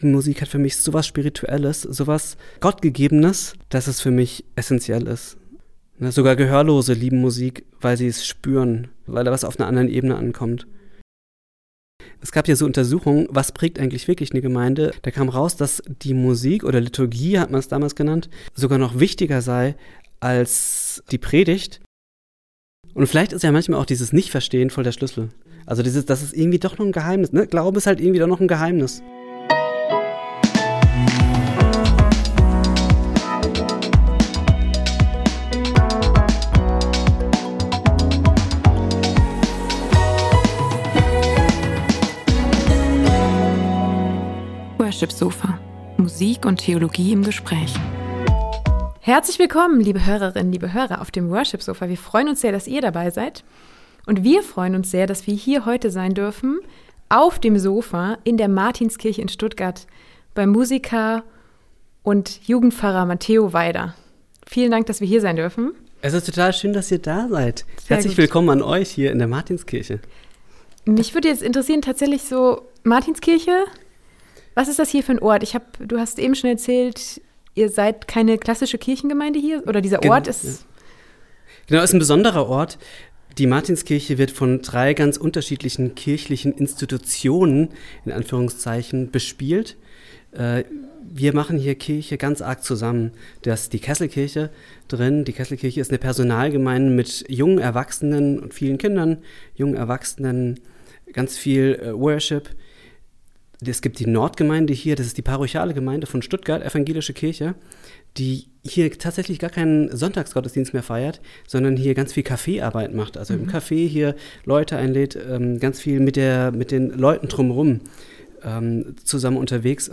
Im interessanten Talk